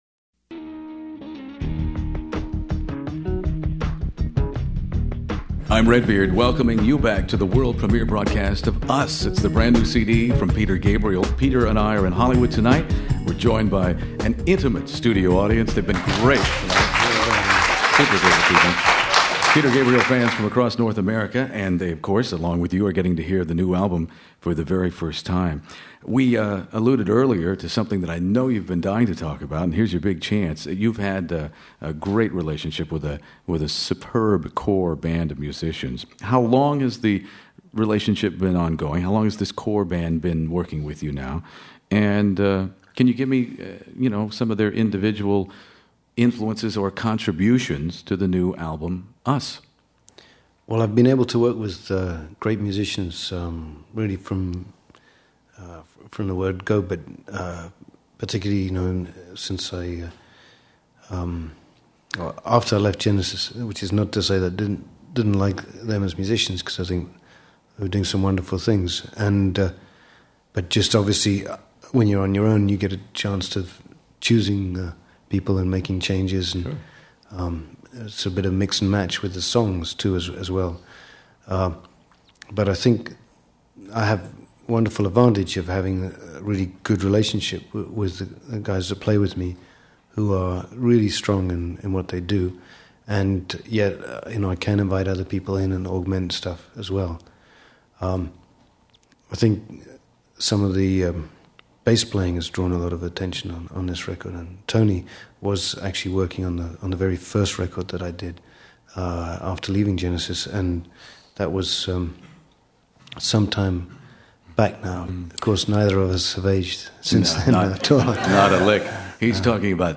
Peter Gabriel interview just "Us" In the Studio